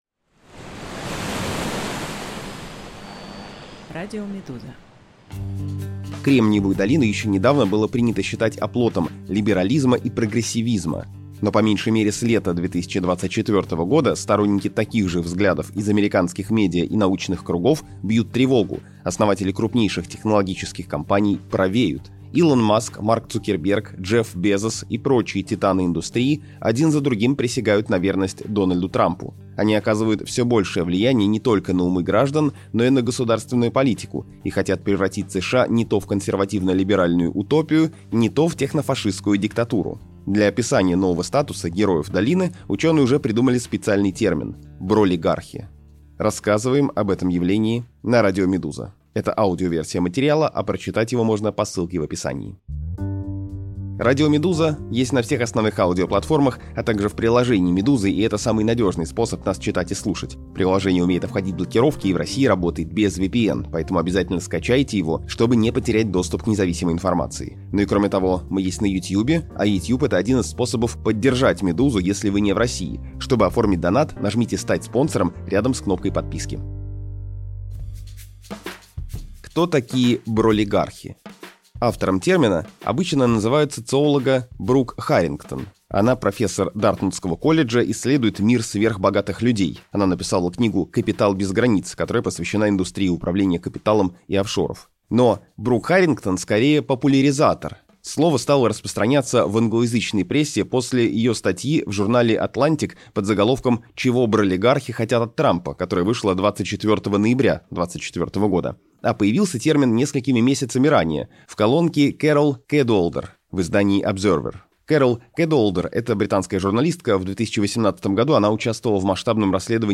Аудиоверсию этого текста слушайте на «Радио Медуза» подкасты Почему Маск, Цукерберг и другие «бролигархи» присягают Трампу? 18 минут 2 месяца назад Кто такие бролигархи Автором термина обычно называют социолога Брук Харрингтон .